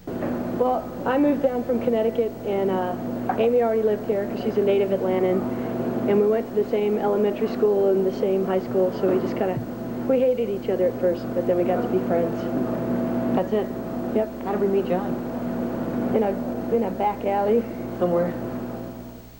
10. interview (0:19)